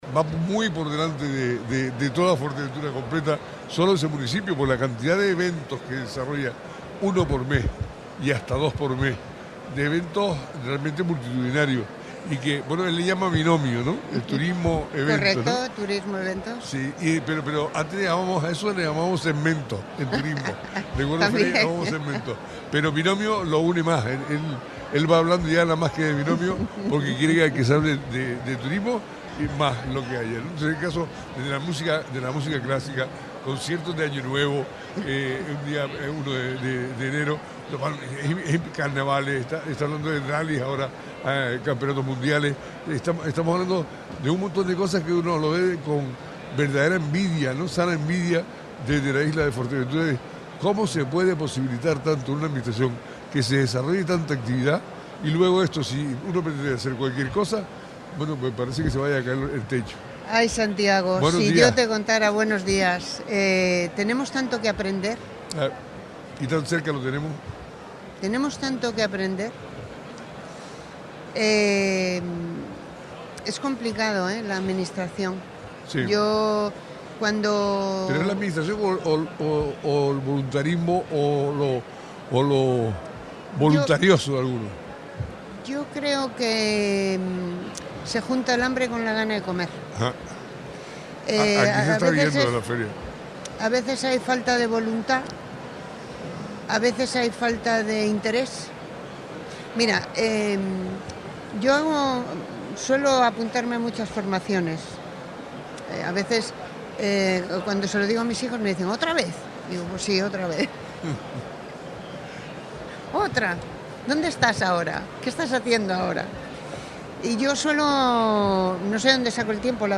Desde la ITB de Berlín, conversamos con Tina da Silva, concejala del Ayuntamiento de Pájara, sobre el trabajo en el Hotel Escuela de Pájara